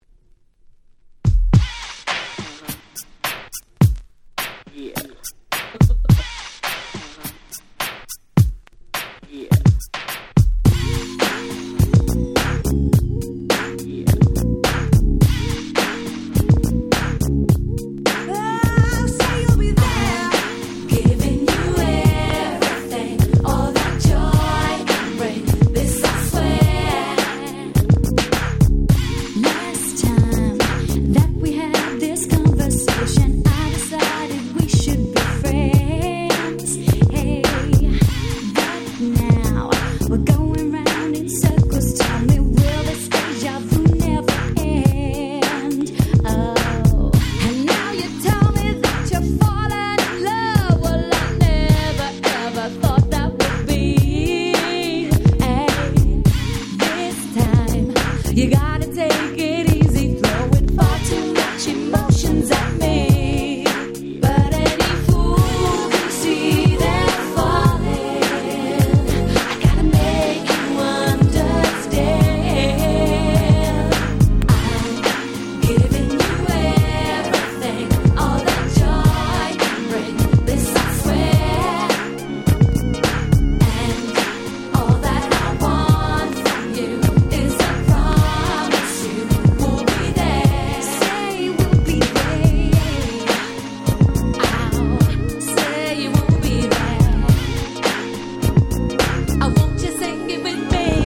96' Super Hit R&B !!